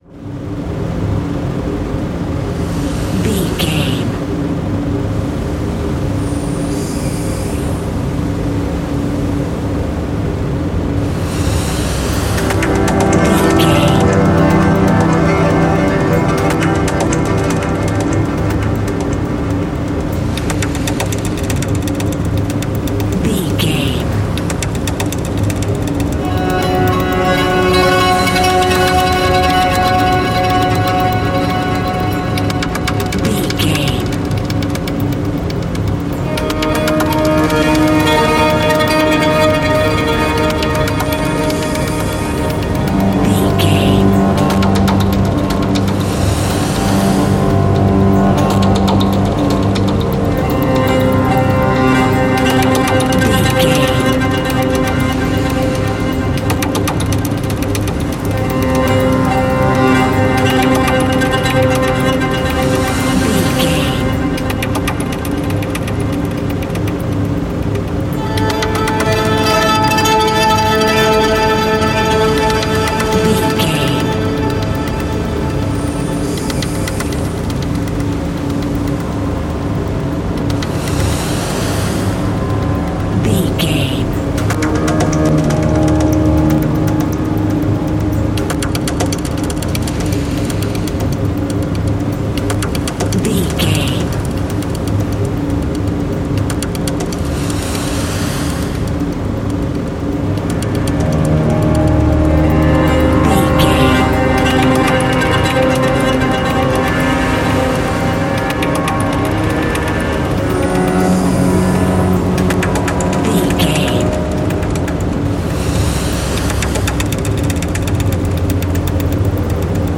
Atonal
tension
ominous
dark
haunting
eerie
synthesiser
keyboards
ambience
pads